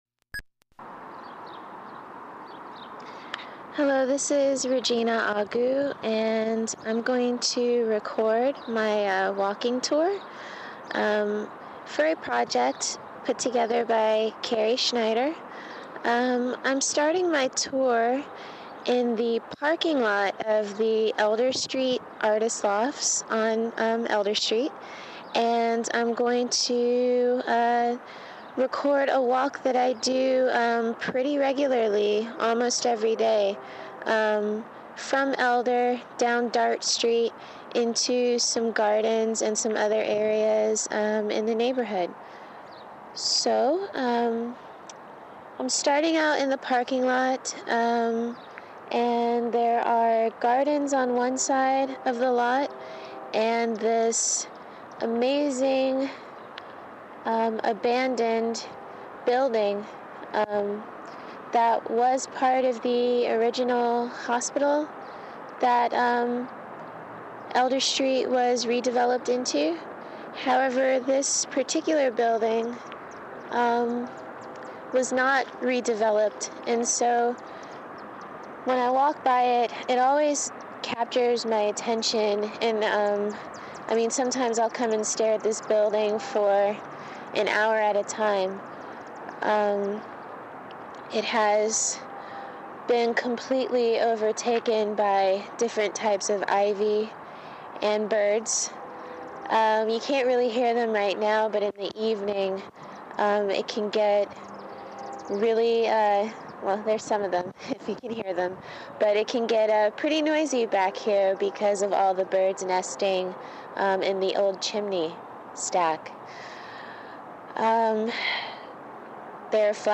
A spot to listen to the meditative and oddly muted freeway whoosh.